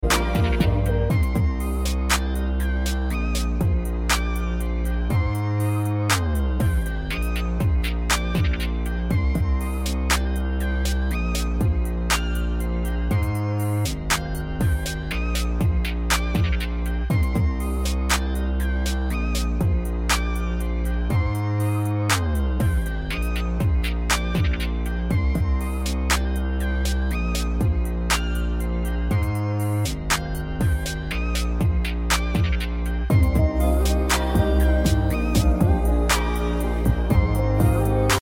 Trap music
Version instrumental